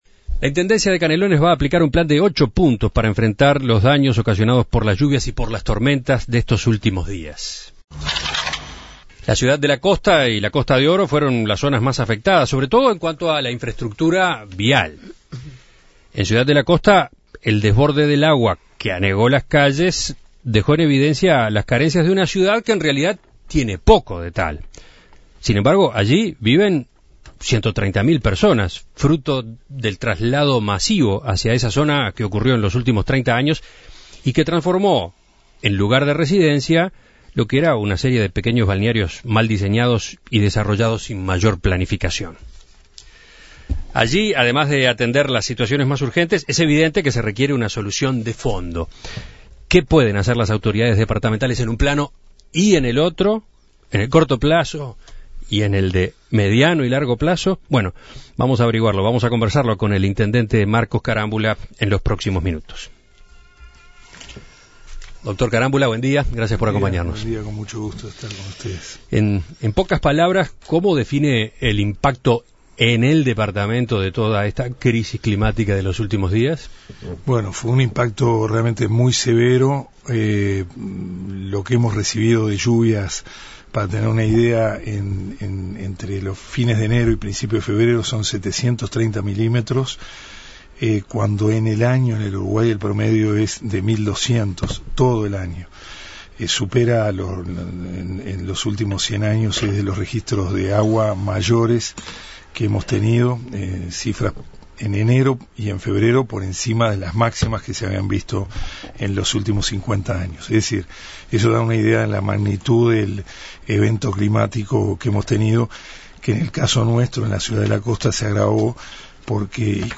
Canelones es uno de los departamentos más afectados por las intensas lluvias de las pasadas jornadas. Desde la Intendencia se va a aplicar un plan de acción de ocho puntos para paliar la situación. En Perspectiva dialogó con el intendente de Canelones, Marcos Carámbula, sobre esta situación.